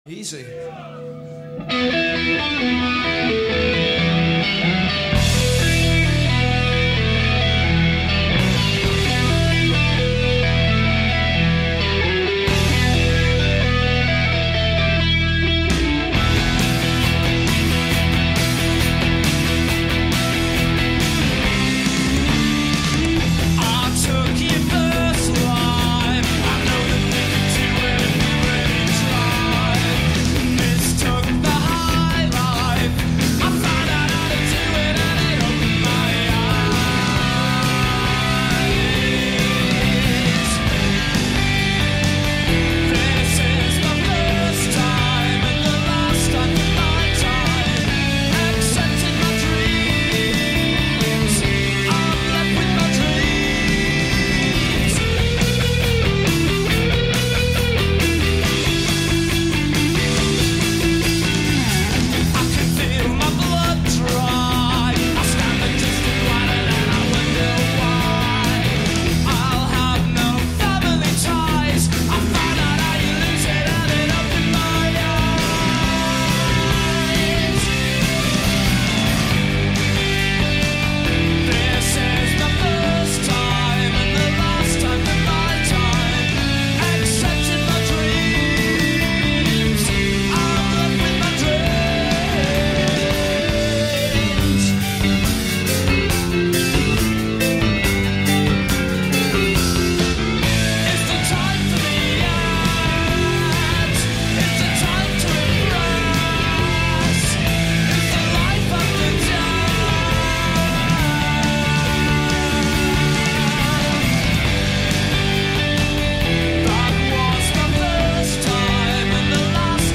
Brit-Pop